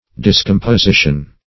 discomposition - definition of discomposition - synonyms, pronunciation, spelling from Free Dictionary
Search Result for " discomposition" : The Collaborative International Dictionary of English v.0.48: Discomposition \Dis*com`po*si"tion\, n. Inconsistency; discordance.